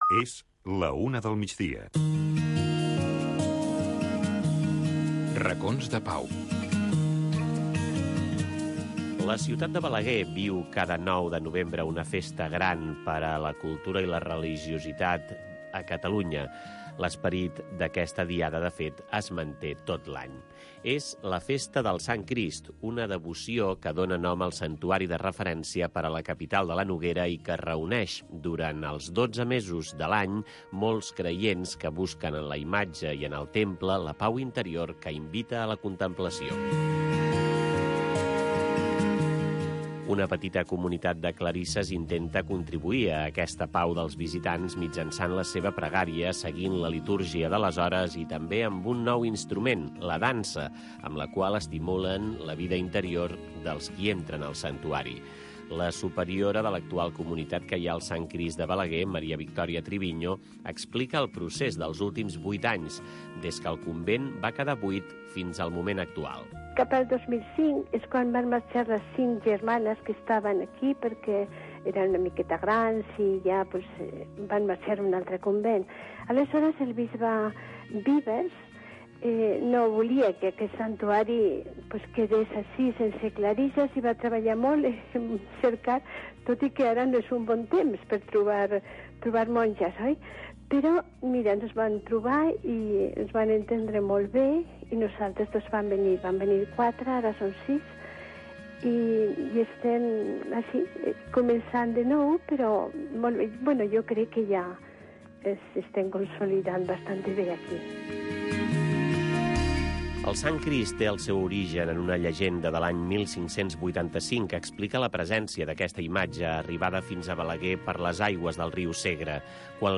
Rutes x Catalunya. Tradicions, festes, cultura, rutes, combinat amb entrevistes i concurs per guanyar molts premis.